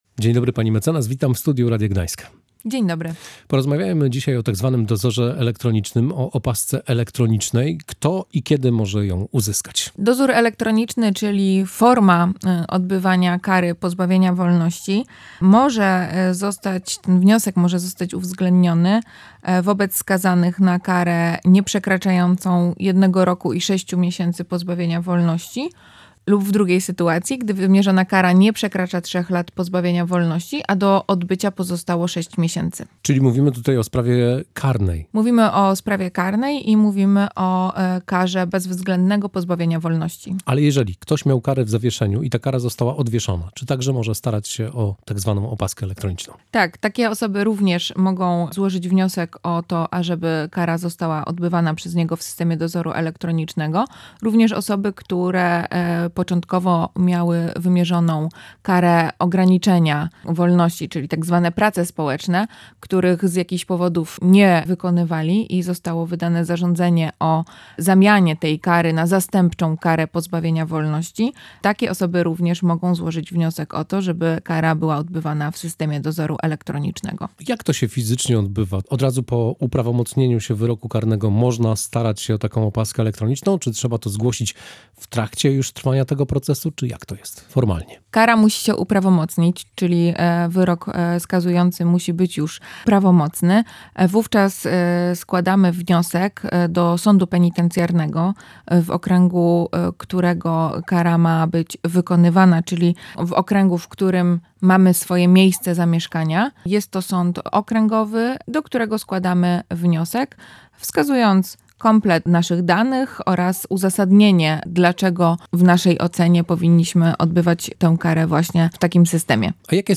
W audycji „Prawo na co dzień” rozmawialiśmy o tak zwanej „opasce elektronicznej”. Kto i kiedy może odbywać karę w takim systemie?